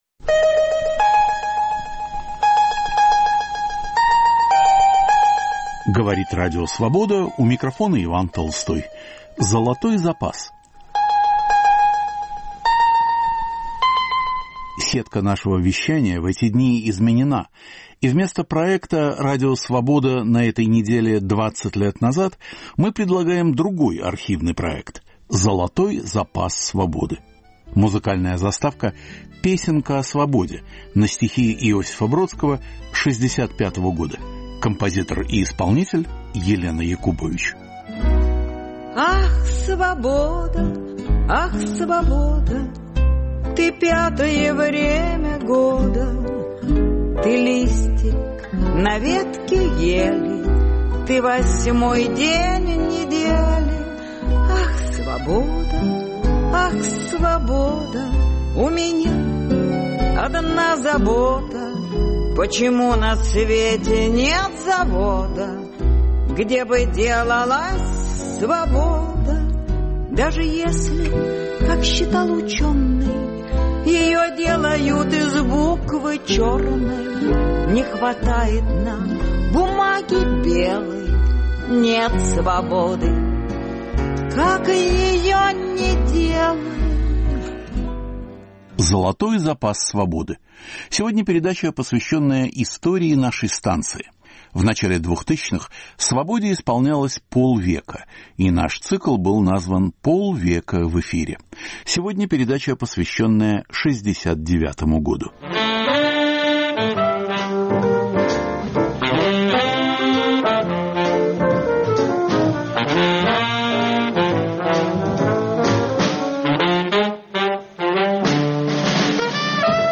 Архивный проект к 50-летию Радио Свобода. Эфир 1969-го: о писателях-невозвращенцах - Анатолии Кузнецове и Михаиле Демине, Светлана Аллилуева читает свою книгу у микрофона "Свободы", на Каннском фестивале - "Андрей Рублев" Тарковского. Первые люди на Луне. Ученые об идеях академика Сахарова.